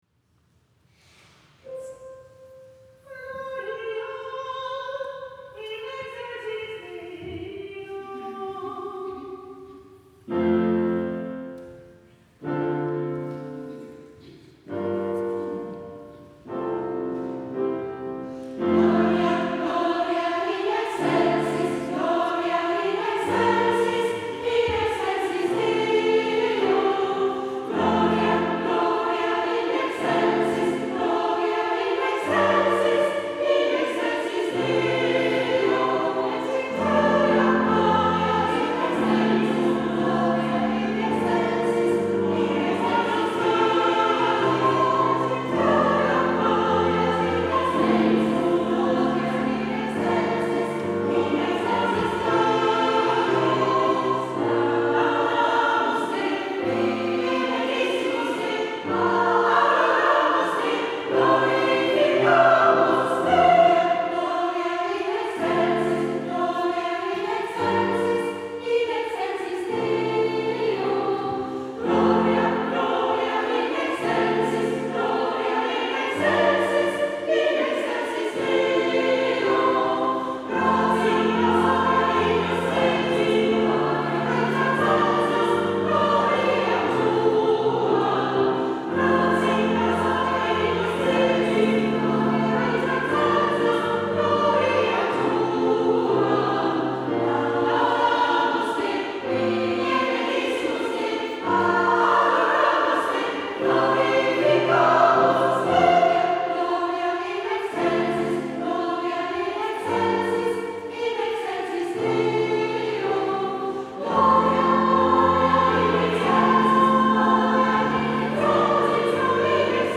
...gelangten Teile der schwungvollen „Missa 4 You(th
Klavier
Bei der diesjährigen Pfingstmesse...
Eine Hörprobe aus unserer Pfingstmesse : Gloria Missa vor youth